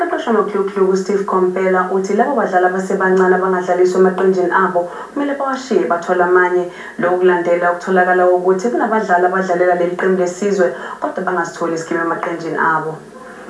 Klapavé zvuky které jsou
v ukázce slyšet, je cvakání zubů,